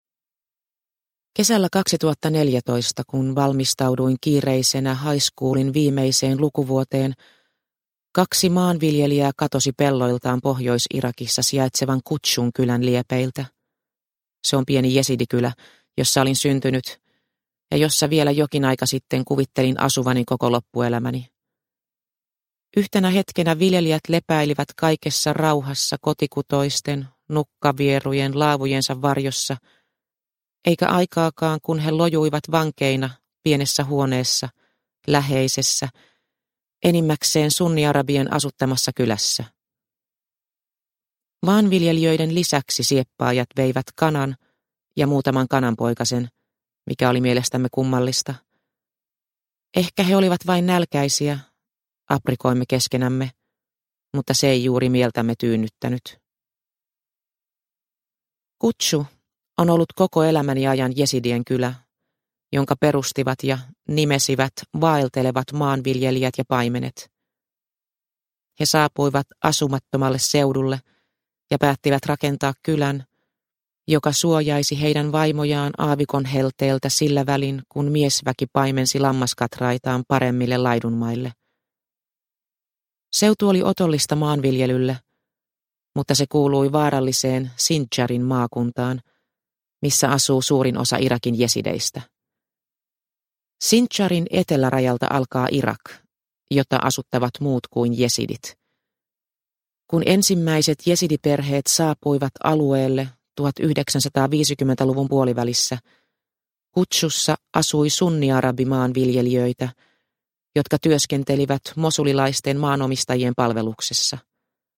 Viimeinen tyttö – Ljudbok – Laddas ner